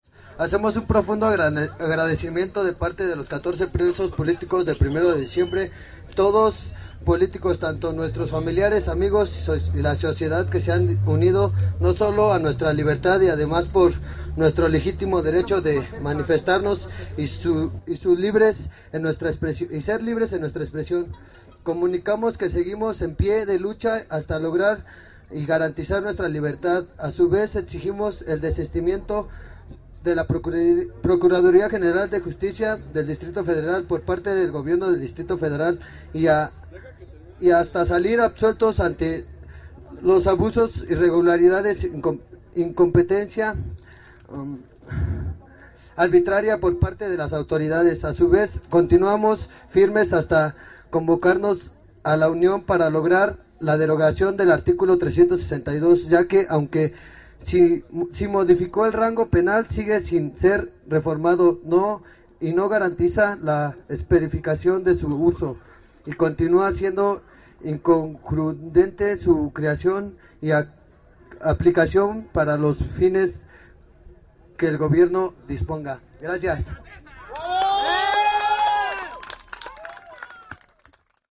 Minutos más tarde dio inicio un acto donde “La Liga de Abogados 1 de Diciembre” y los ahora ex Presos Políticos, darían sus respectivos pronunciamientos.
Pronunciamiento de los ex Presos Políticos